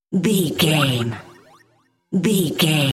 Sound Effects
Atonal
magical
mystical